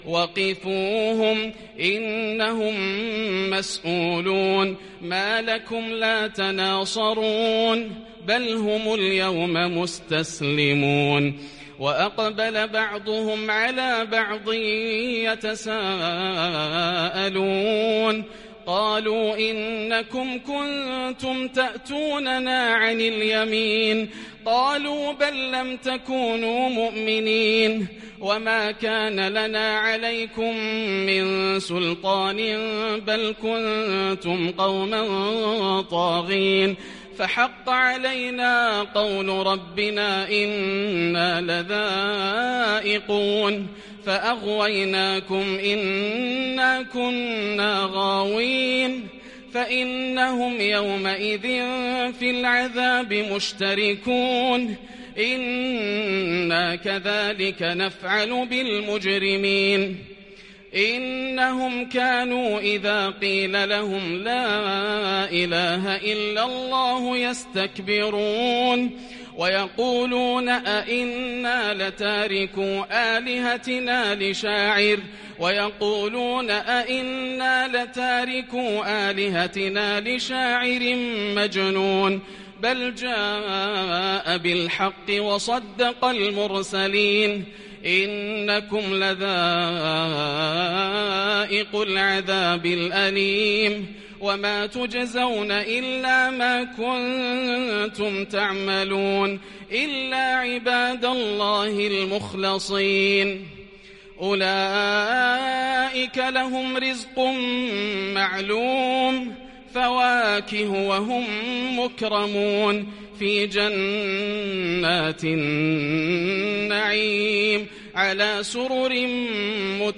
تلاوة مبكية من سورة الصافات من قيام ليلة 25 > الروائع > رمضان 1441هـ > التراويح - تلاوات ياسر الدوسري